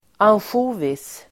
Ladda ner uttalet
ansjovis substantiv, tinned sprats cured in brine Uttal: [ansj'o:vis] Böjningar: ansjovisen, ansjovisar Definition: inlagd och starkt kryddad skarpsill (the Swedish version of anchovies) Anchovy , ansjovis